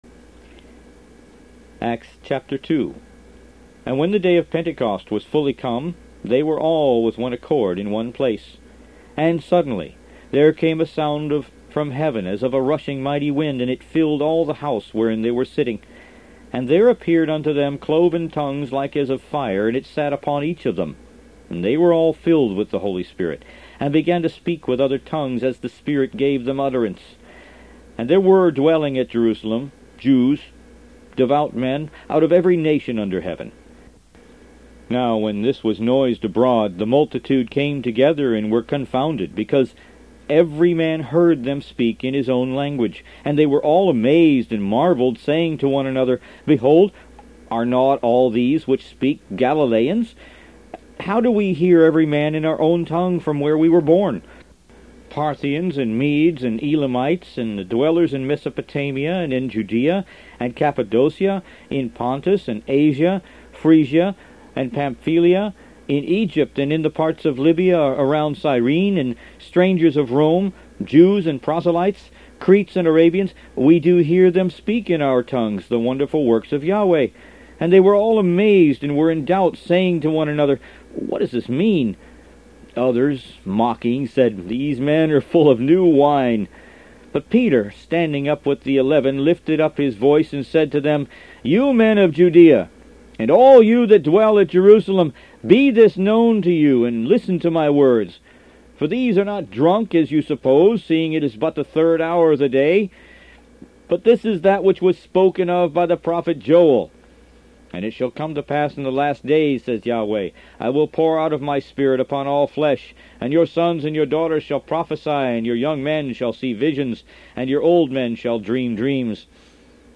Root > BOOKS > Biblical (Books) > Audio Bibles > Messianic Bible - Audiobook > 05 The Book Of Acts